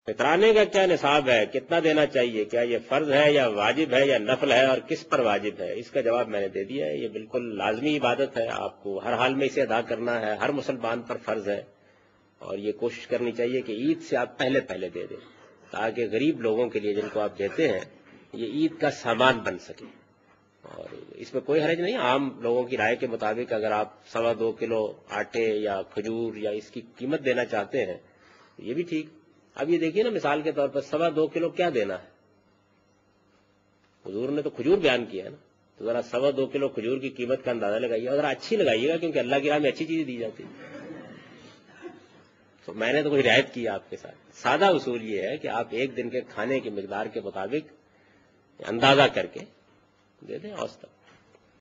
Javed Ahmed Ghamidi answering a question regarding Fitrana.